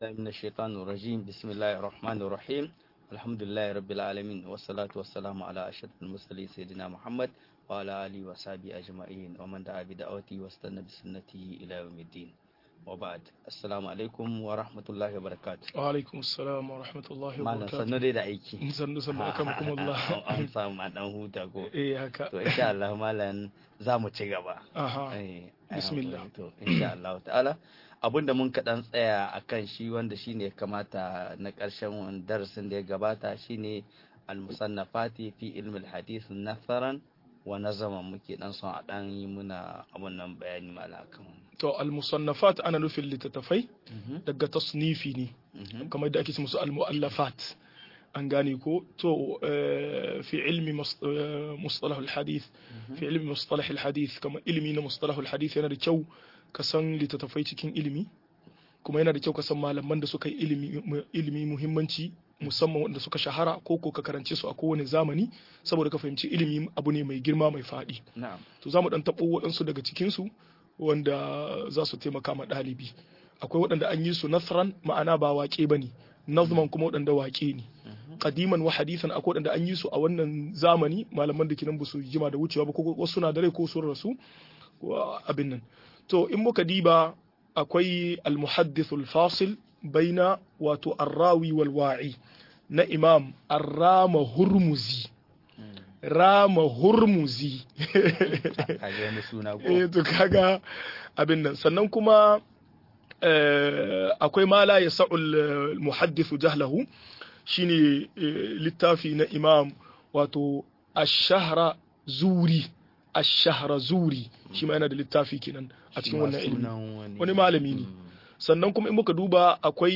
Yadda ake karatun hadissi - MUHADARA